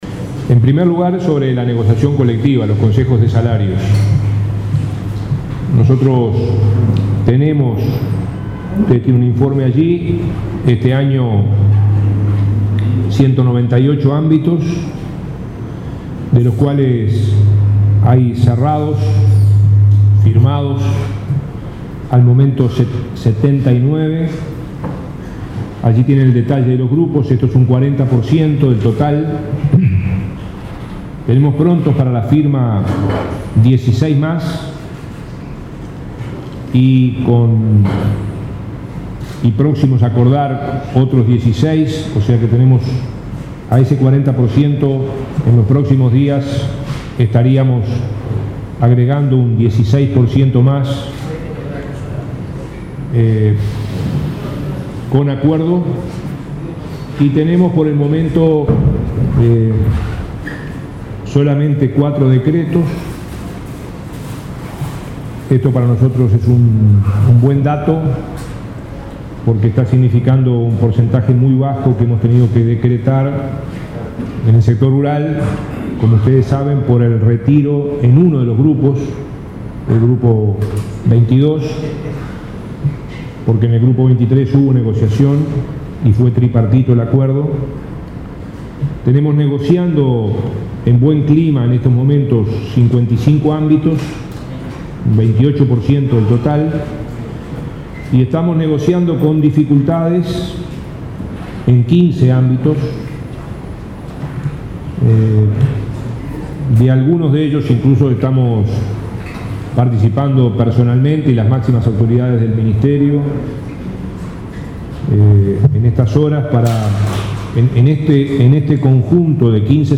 El ministro Ernesto Murro informó a la prensa que de los ámbitos de negociación colectiva, se cerraron 79, 16 están para firmar y otros 16 próximos a hacerlo.